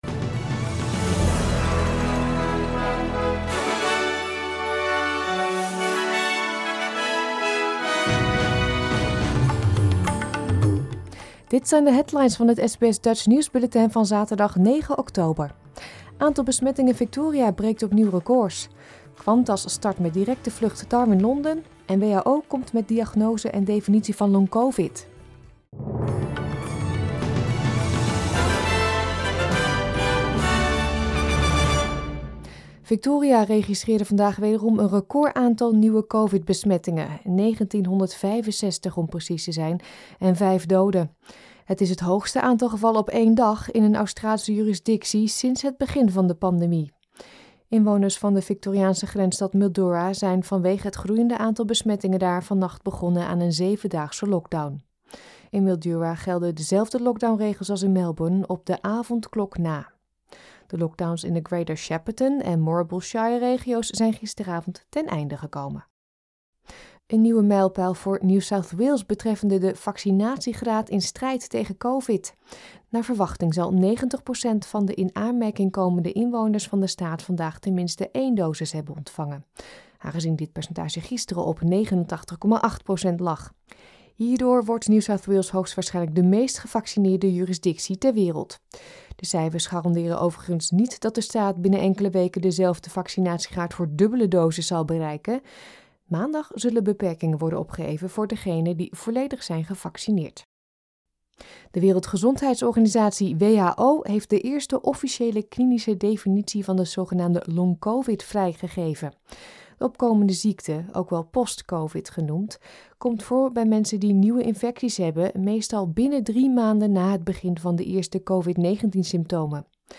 Nederlands/Australisch SBS Dutch nieuwsbulletin van zaterdag 9 oktober 2021